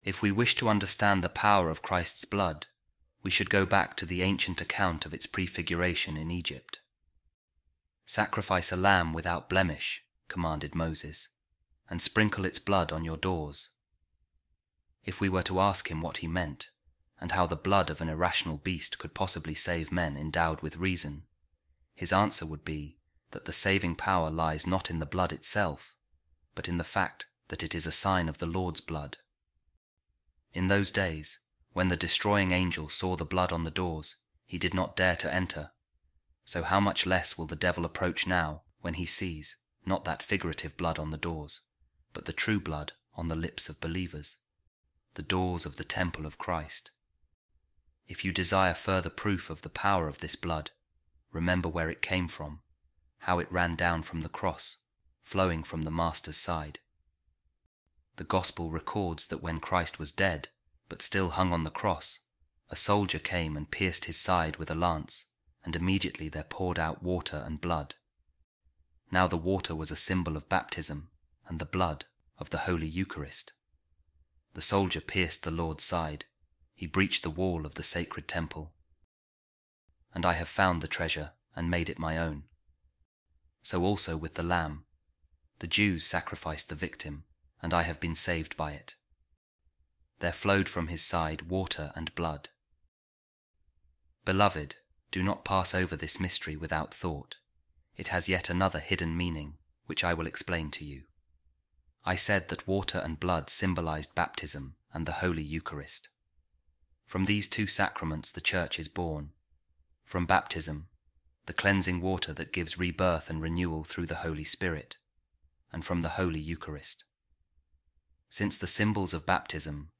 Office Of Readings | Good Friday | A Reading From The Instructions Of Saint John Chrysostom To Catechumens